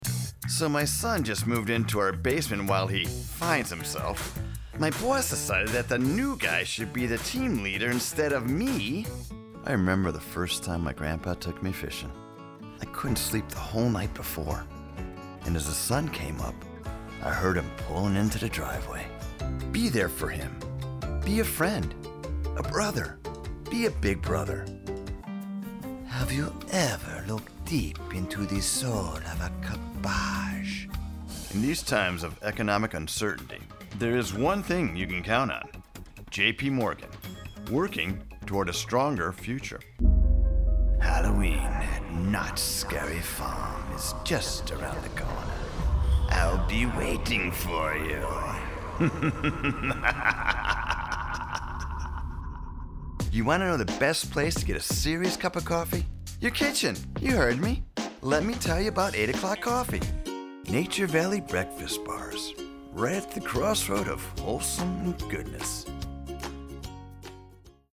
Male
English (North American)
Adult (30-50)
Full range of deep domineering authoritative to warm and heartfelt. Full range of animated voices.
Television Spots
Commercial